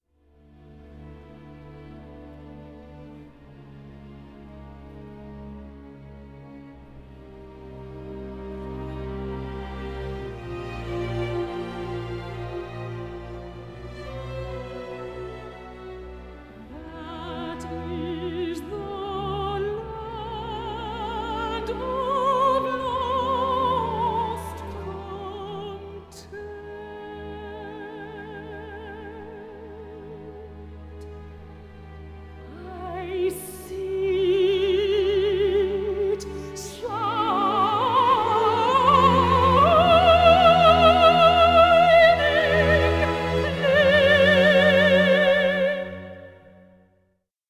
but being set only for voice and strings